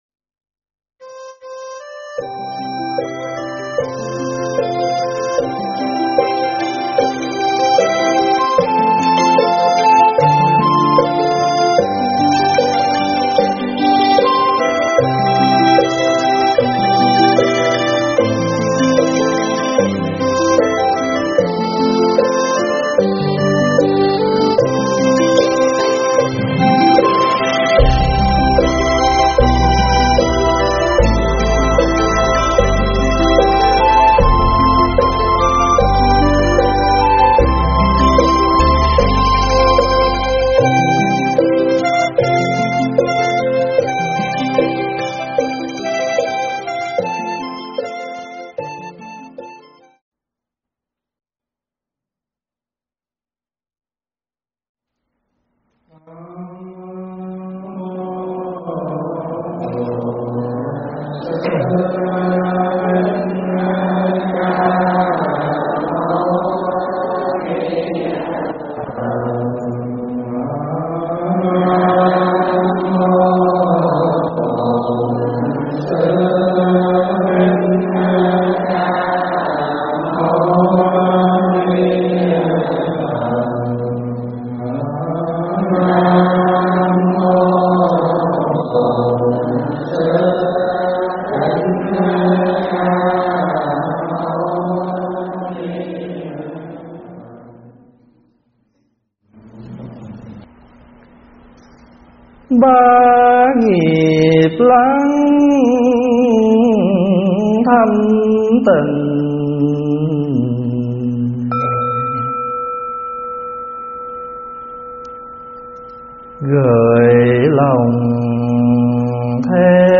thuyết pháp
giảng tại Tu Viện Tây Thiên